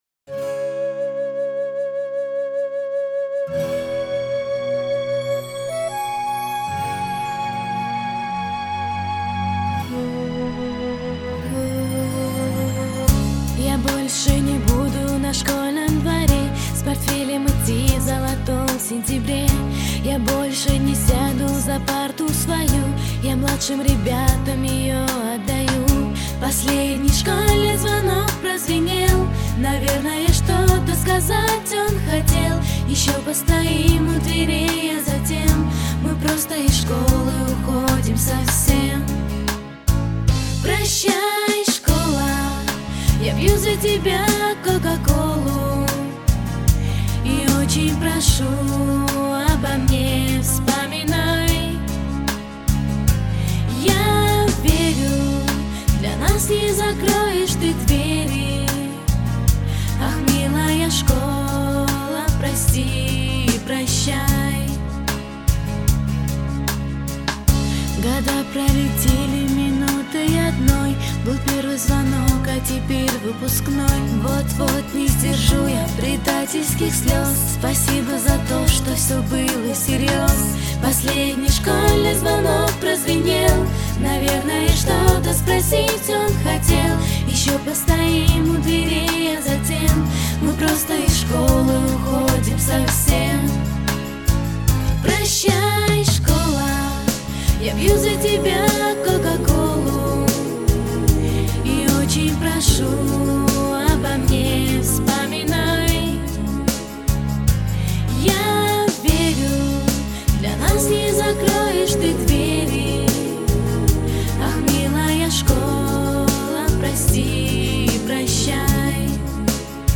песня на выпускной